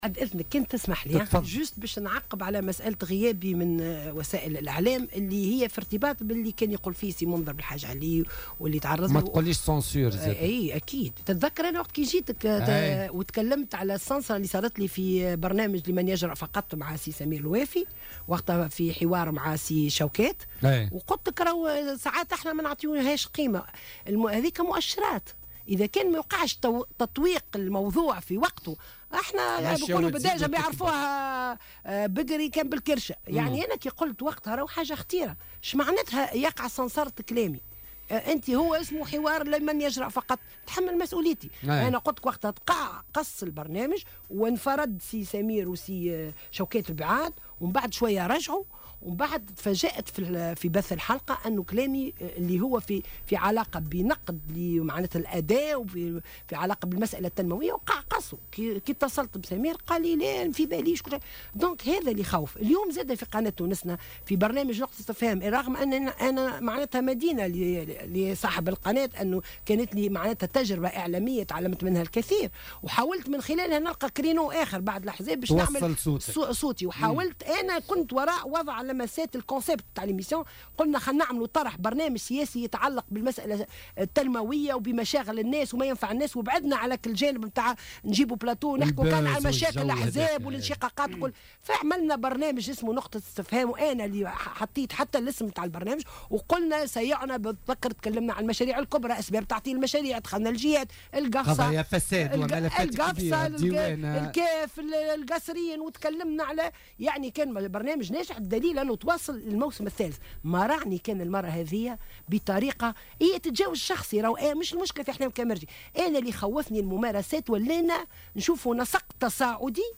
وأضافت في مداخلة لها اليوم في برنامج "بوليتيكا" أنها كانت بصدد الإعداد لمداخلة حول ملف الفسفاط في البرنامج المذكور قبل أن تتلقى اتصالا من إدارة القناة المذكورة لتخبرها بأنها لن تحضر الحصة بسبب تعلات واهية.